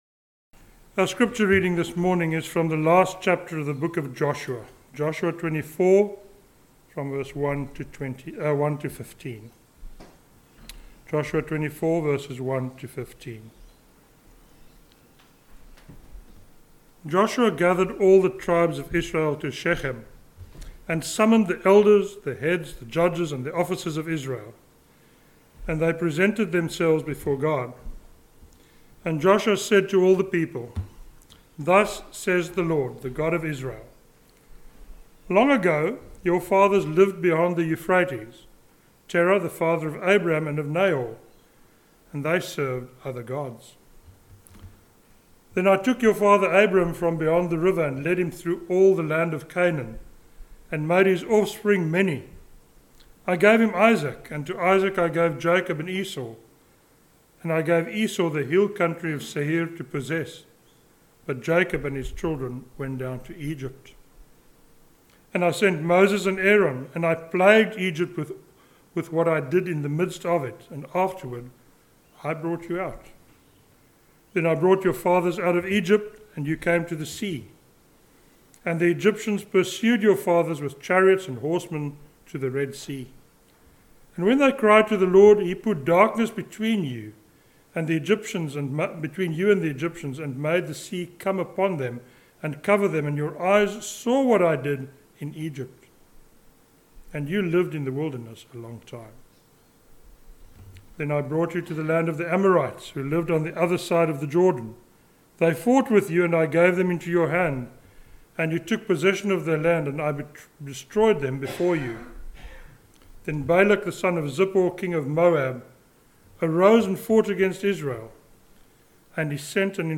a sermon on Joshua 24:1-15